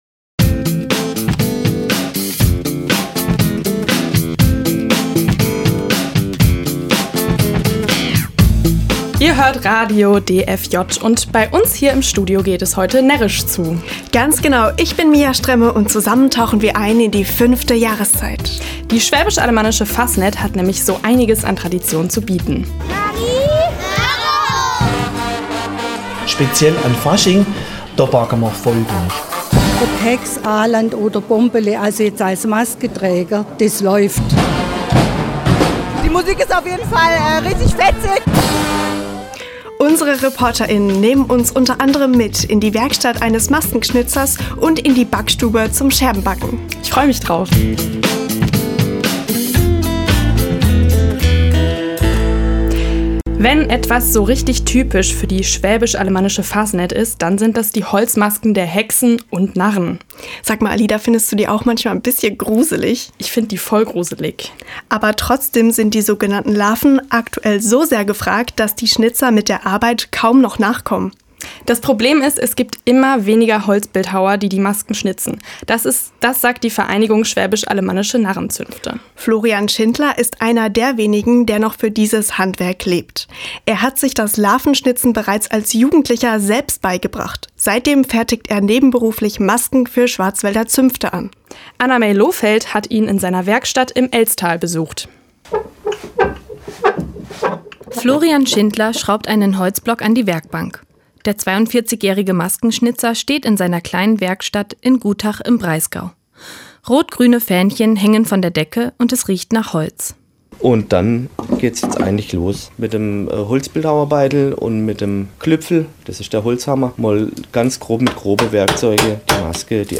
Juni 2023: BZ-Magazin zum Thema Aktivismus Hörfunk Am Ende des Wintersemesters sind die Studierenden immer zwei Wochen lang beim SWR Studio in Freiburg und erarbeiten dort eine komplette Radiosendung.